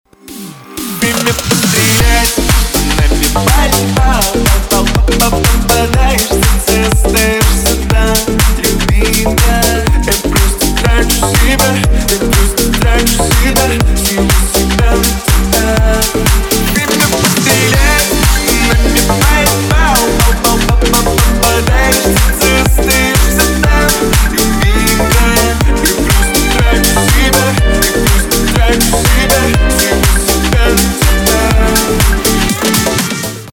• Качество: 320, Stereo
мужской вокал
громкие
веселые
Club House